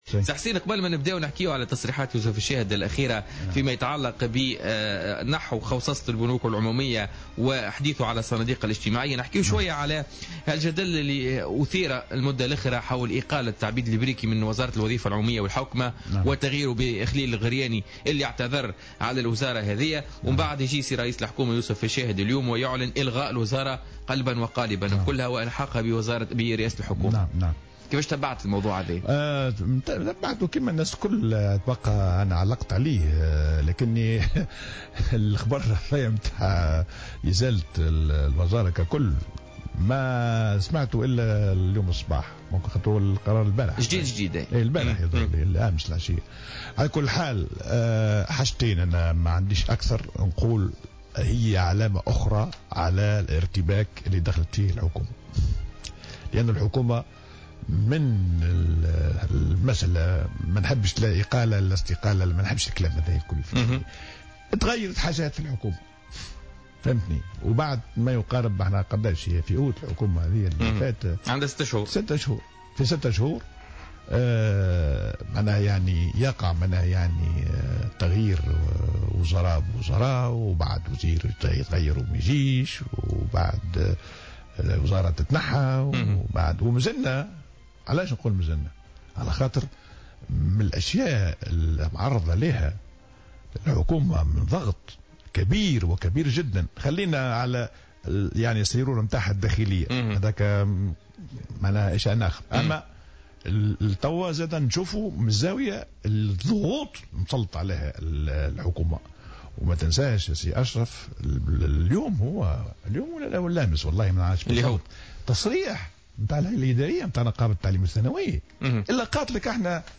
اعتبر وزير المالية الأسبق والخبير الاقتصادي حسين الديماسي خلال حضوره اليوم في برنامج "بوليتكا"، التحوير الوزاري وقرار رئيس الحكومة يوسف الشاهد حذف وزارة الوظيفة العمومية والحوكمة وإلحاق الهياكل والمؤسسات الراجعة إليها بالنظر لدى رئاسة الحكومة، علامة على ارتباك الحكومة وضعفها.